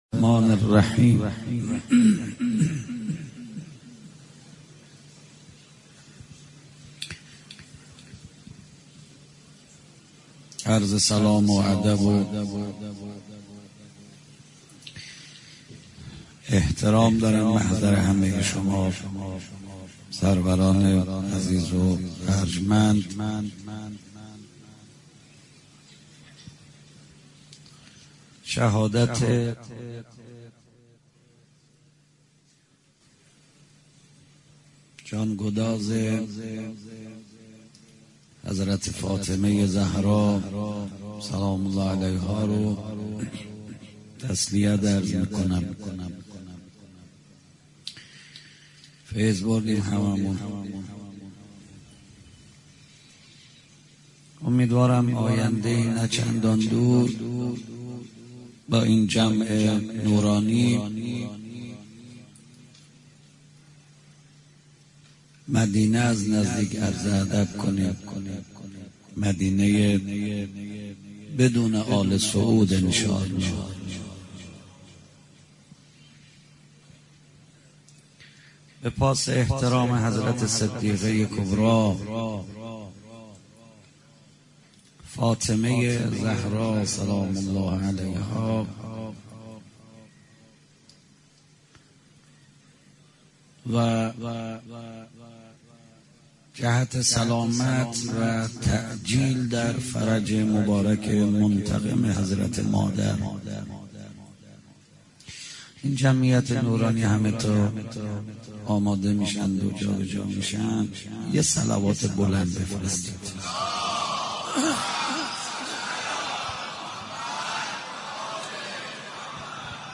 دانلود مداحی شهادت حضرت زهرا
شب اول فاطمیه
شور ایام فاطمیه